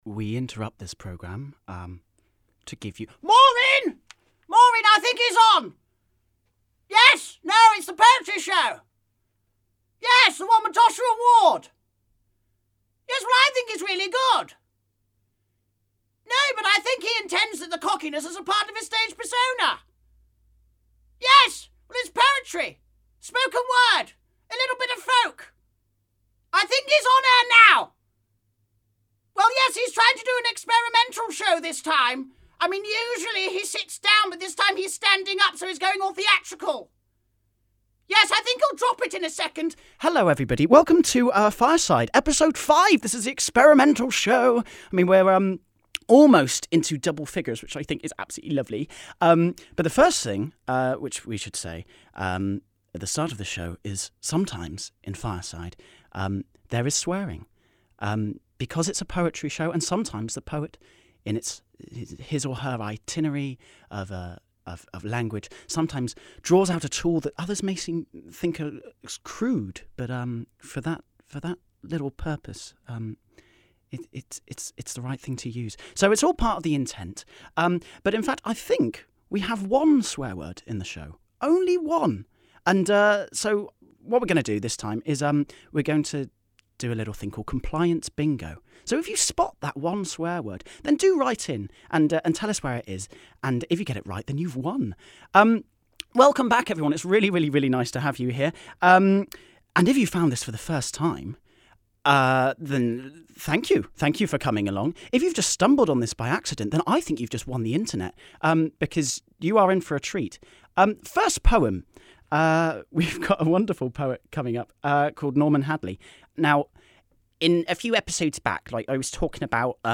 Fireside is the folk, poetry and whatever-we-like sharing space.